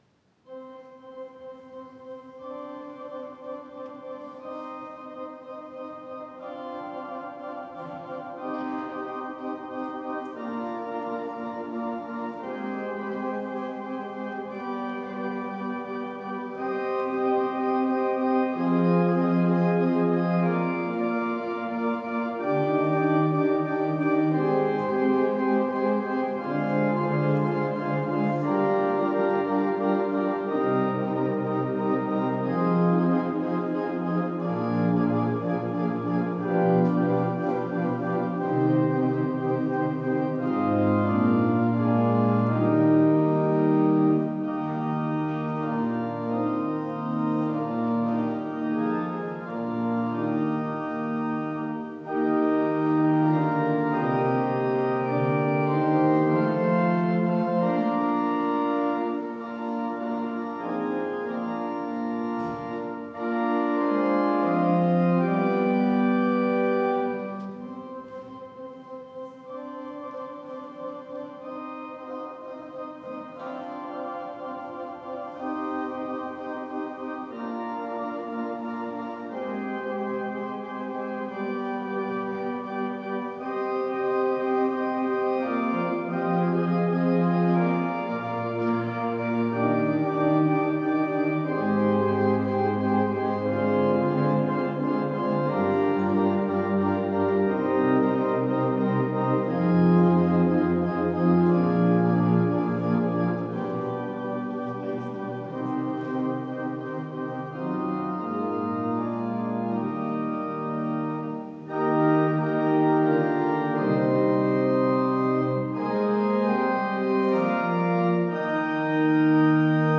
14. Orgelstück zum Ausgang
Audiomitschnitt unseres Gottesdienstes vom 2. Sonntag nach Epipanias 2026.